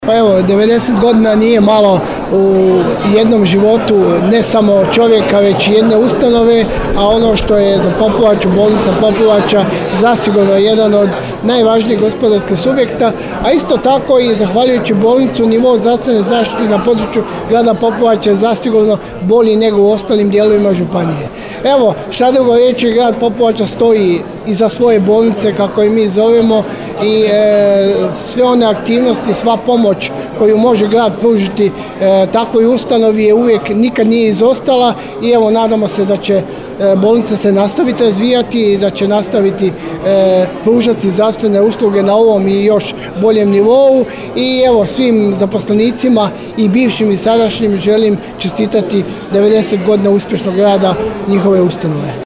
U povodu obilježavanja 90 godina Neuropsihijatrijske bolnice „dr. Ivan Barbot” Popovača u petak, 22. studenog 2024. godine, u Domu kulture u Popovači održana je prigodna svečanost.
Čestitku na uspješnom radu ove zdravstvene ustanove uputio je i gradonačelnik Popovače Josip Mišković